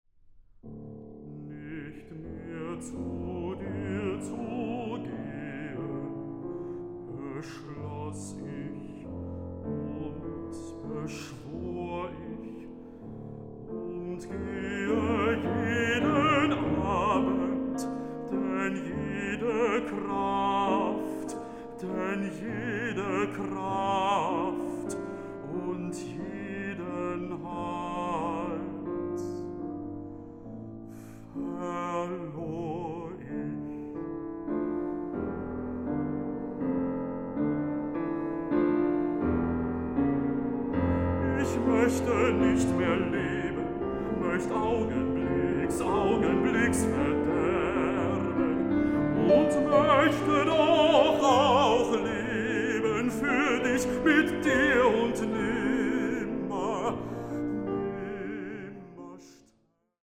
Recording: Mendelssohn-Saal, Gewandhaus Leipzig, 2025